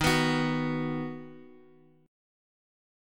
D#m#5 chord {x x 1 x 0 2} chord
Dsharp-Minor Sharp 5th-Dsharp-x,x,1,x,0,2.m4a